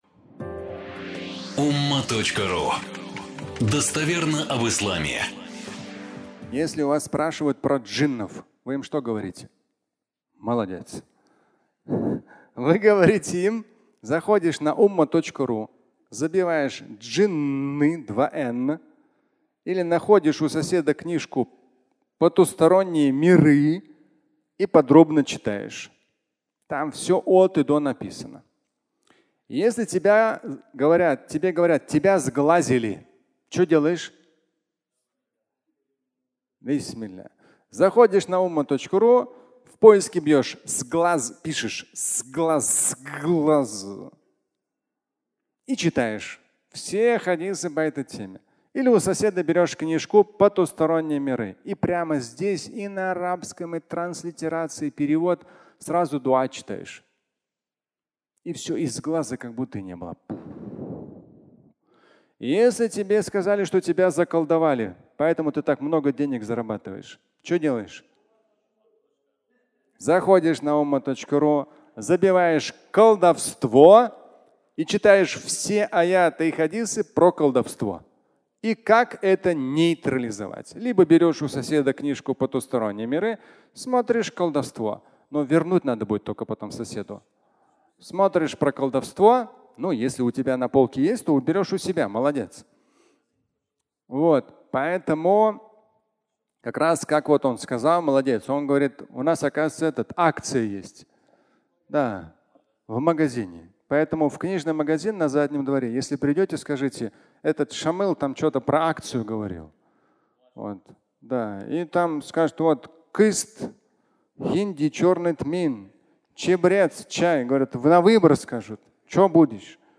Джинны. Сглаз и колдовство (аудиолекция)